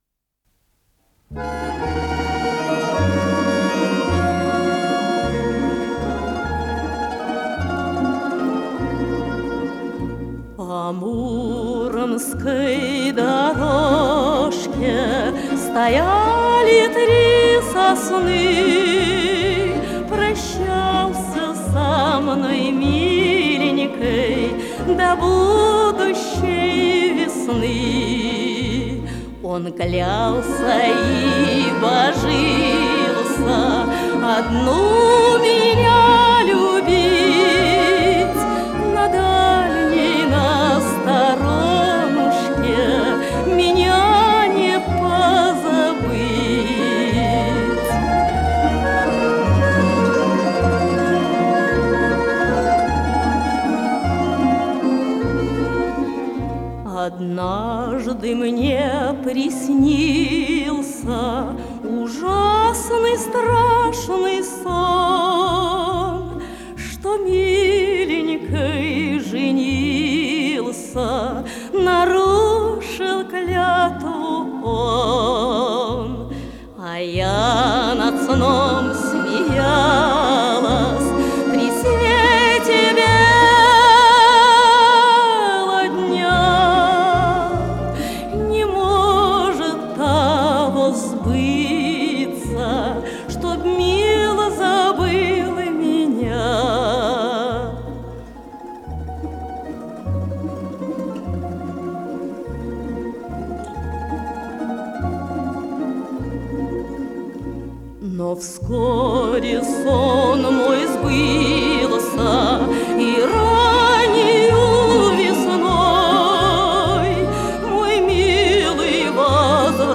с профессиональной магнитной ленты
КомпозиторыРусская народная
АккомпаниментАнсамбль народных инструментов
ВариантДубль моно